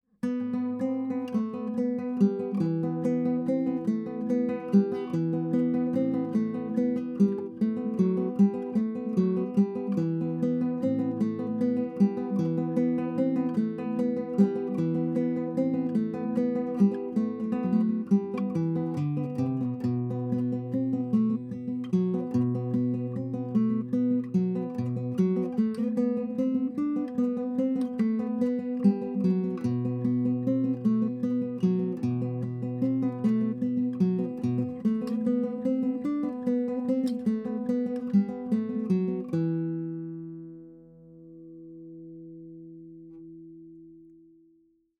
Questo modello di chitarra classica ci offre un suono unico grazie al suo top in cedro massello, al manico in mogano e alla tastiera in palissandro indiano.
Si contraddistingue per un suono caldo e piacevole e per una finitura lucida, a differenza delle meccaniche JTC-5 e nickel silver.